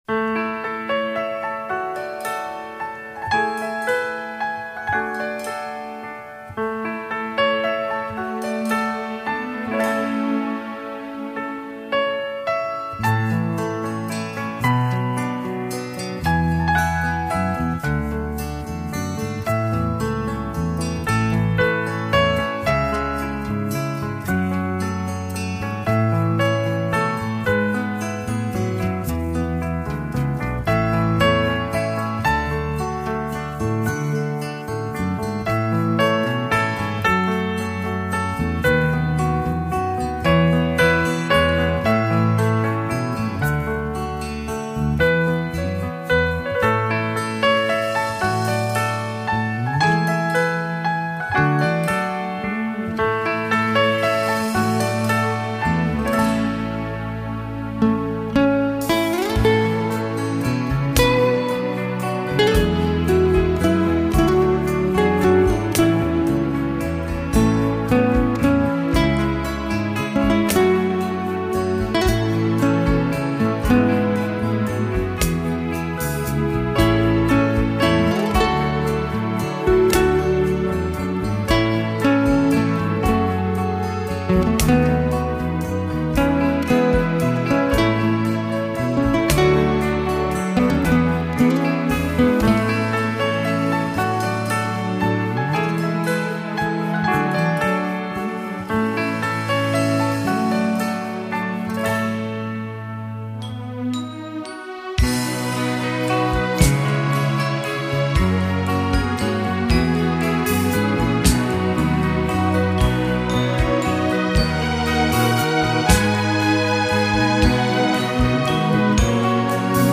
透明,神秘的音色充滿了朦朧的氛圍,為人們带來内心的平静。
歡快的曲調. 總能帶給人晴空萬裹的感覺